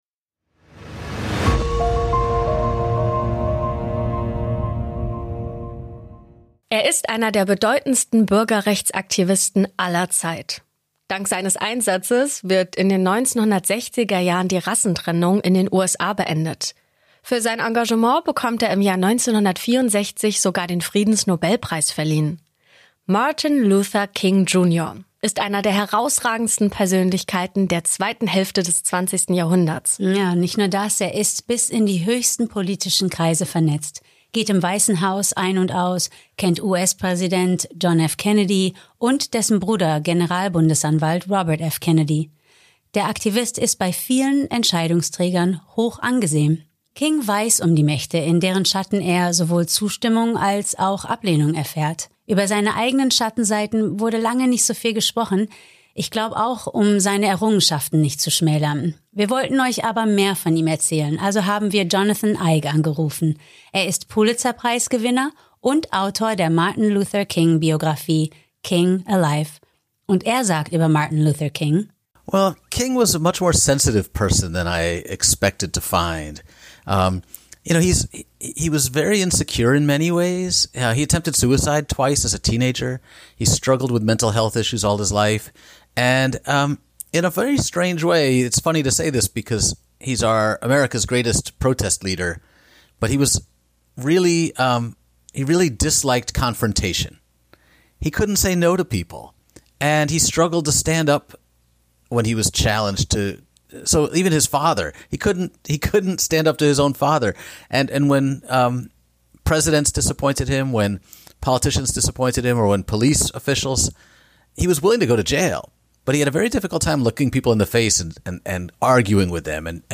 Doch damals wie heute ranken sich Zweifel um die Rolle des vermeintlichen Mörders und die wahren Hintergründe des Verbrechens. Gerüchte über eine Beteiligung des FBI, der CIA, der US-Armee oder der Memphis-Polizei kursierten schnell – und werden bis heute diskutiert. Wir sprechen auch mit Jonathan Eig, der neue Erkenntnisse zu dem Fall zutage führt, und finden mit euch raus, was wirklich an diesem verhängisvollen Tag in Memphis passiert ist.